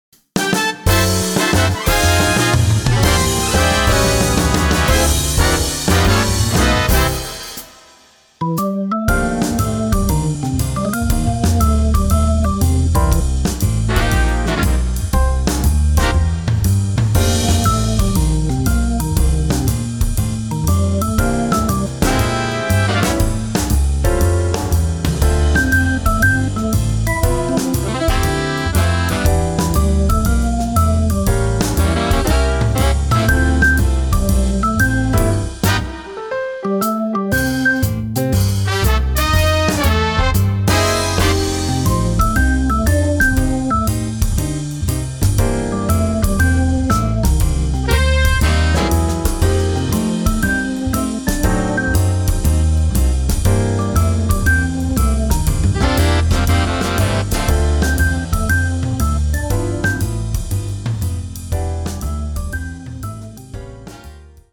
smooth jazz club 17